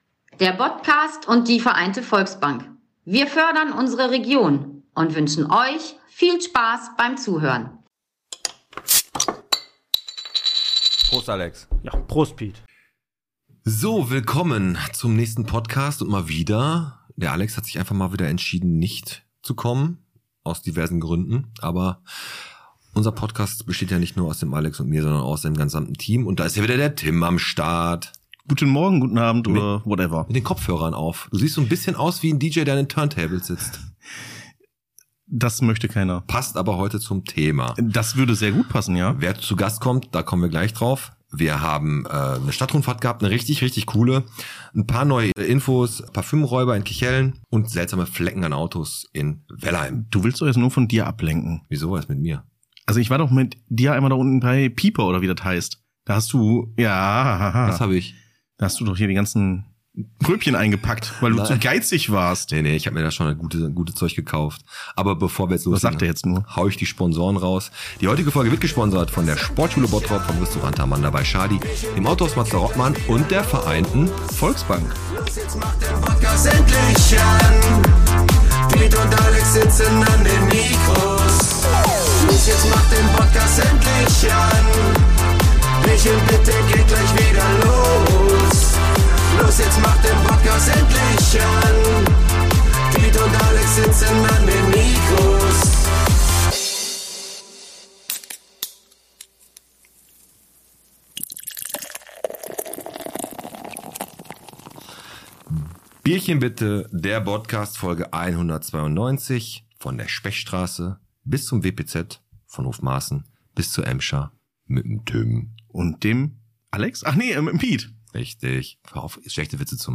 Beschreibung vor 1 Jahr Heute gibts wieder einen ganz besonderen Gast im Bottcast denn Orhan Terzi besser bekannt als DJ Quicksilver ist bei uns an den Mikros.